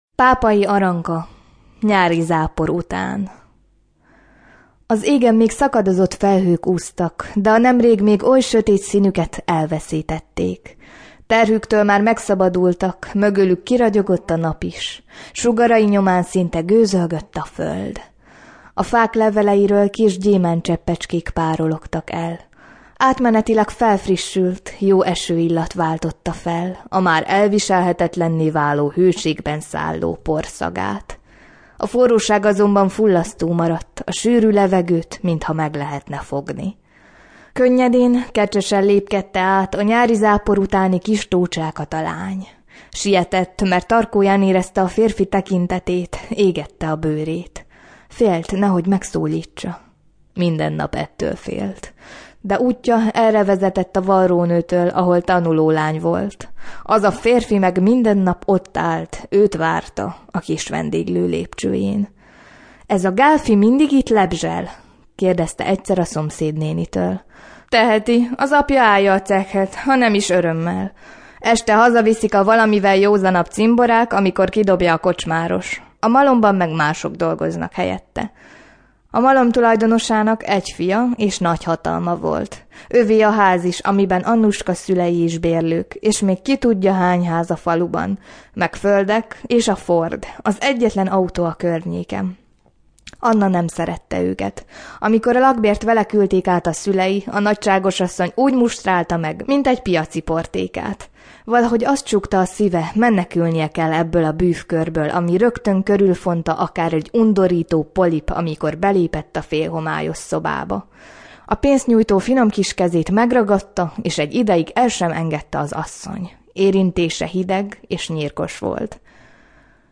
A hangfelvétel az Irodalmi Rádió stúdiójában készült.